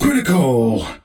bl_hit_critical.ogg